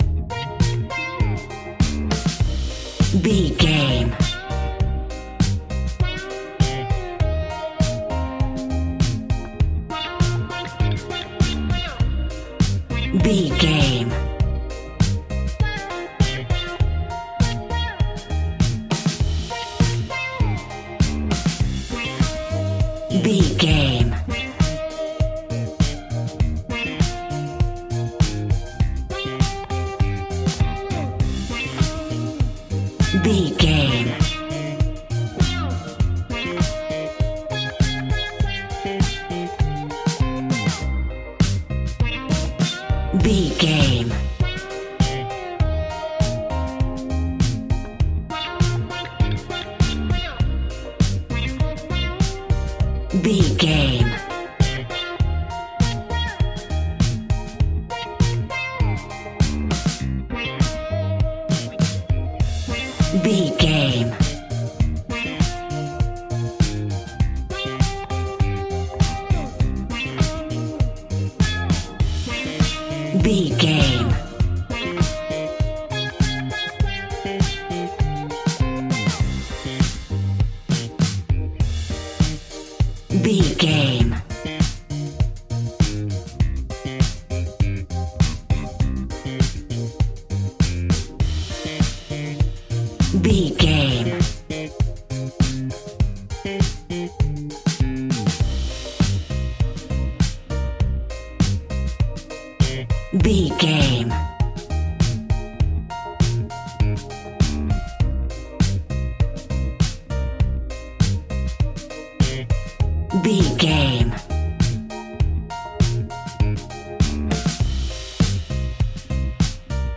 Ionian/Major
D
house
electro dance
synths
techno
trance
instrumentals